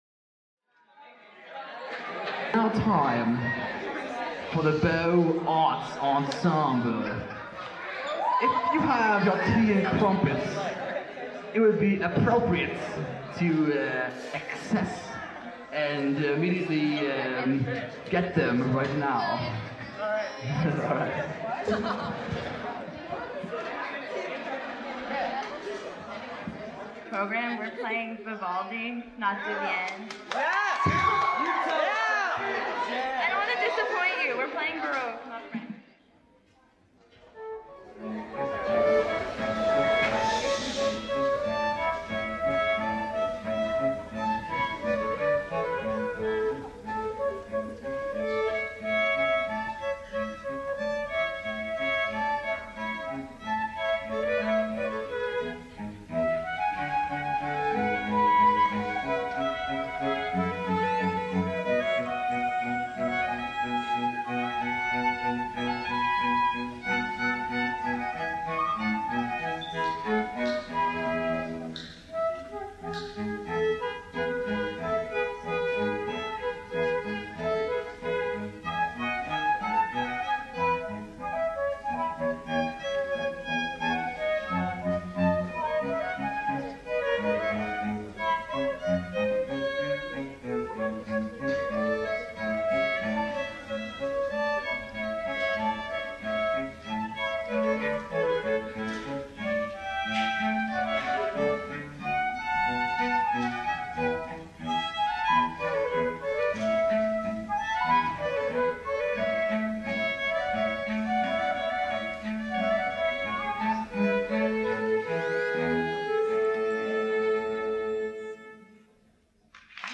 10th and 12th grades
Beaux Arts Ensemble – 2005-2006